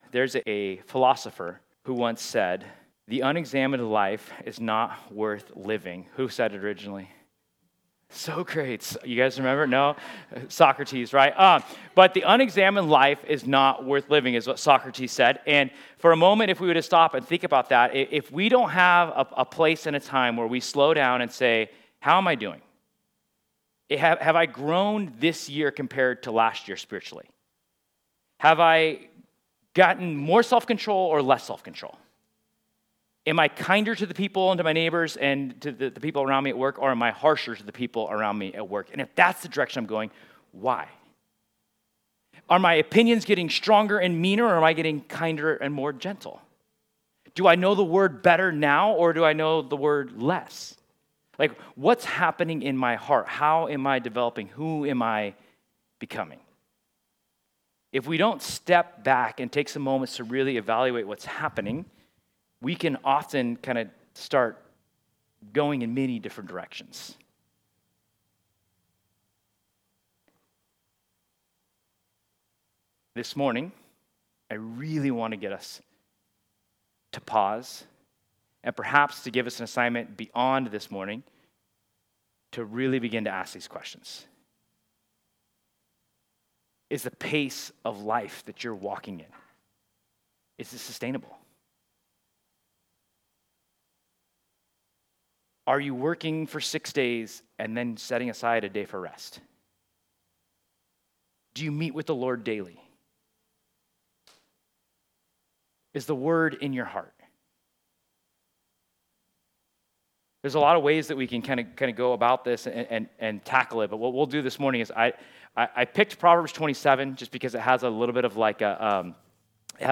This Sunday, join us for a special New Year message, “Out of Auto Pilot.” Together we’ll walk through Proverbs 27, using it as a spiritual mirror—a litmus test for where our faith is right now and where God may be inviting us to grow as we move into 2026.